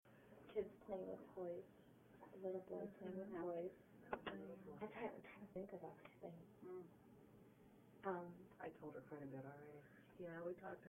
In a client's kitchen We were talking about a ghost child that had been spotted years earlier and whom the client still feels is present. A small voice comes in briefly as we are speaking.